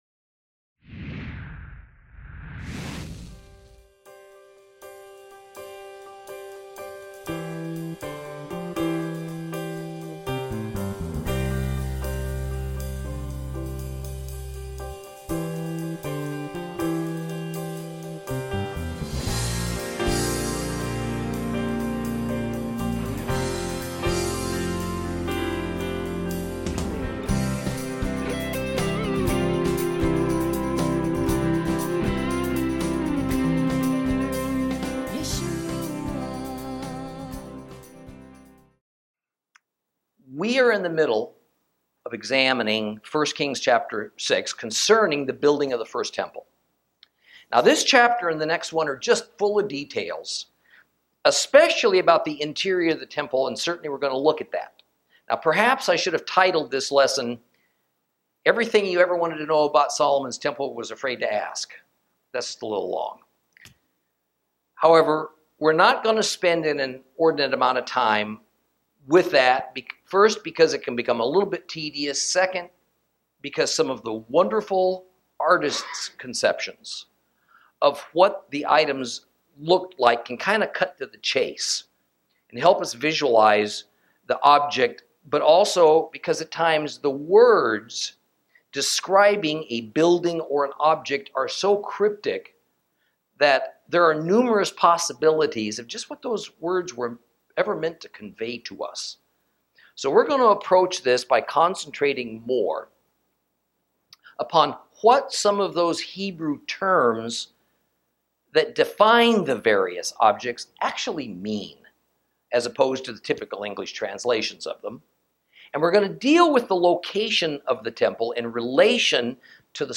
Lesson 11 – 1 Kings 6 and 7